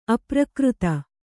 ♪ aprakřta